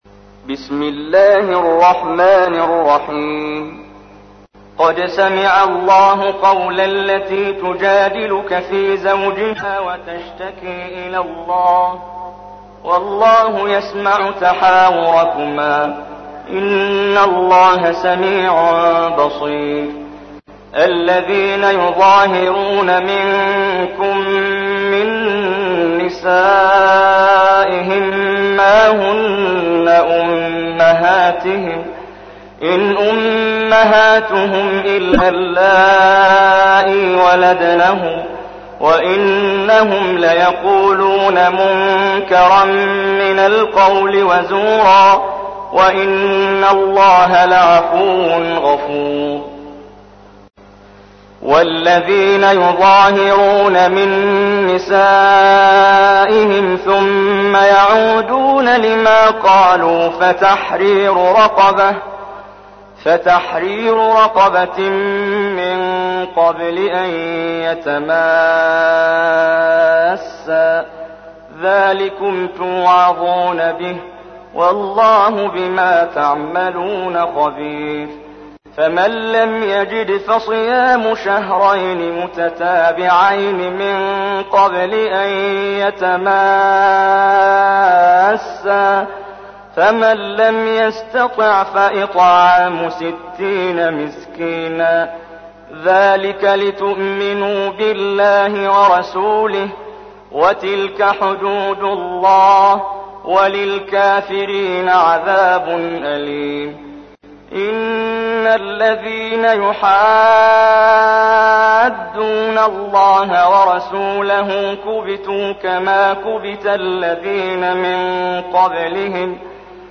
تحميل : 58. سورة المجادلة / القارئ محمد جبريل / القرآن الكريم / موقع يا حسين